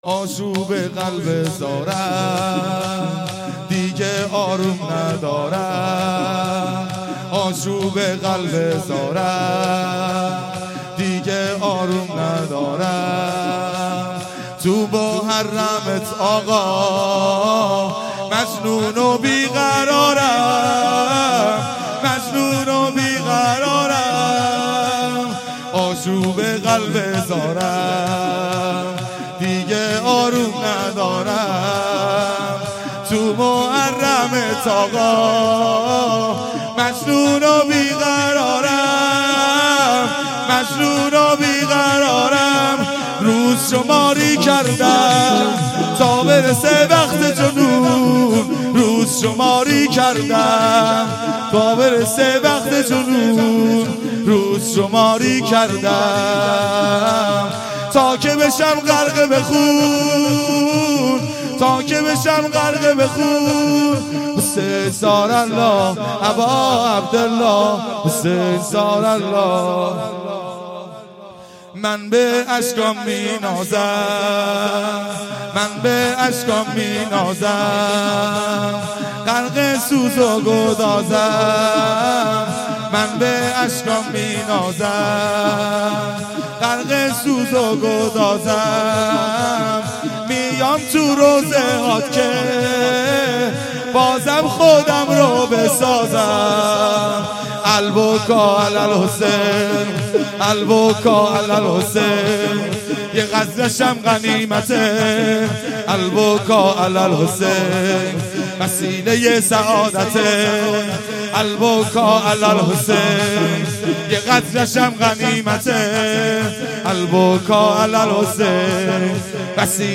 اصوات مراسم سیاهپوشان ودهه اول محرم۹۷هییت شباب الحسین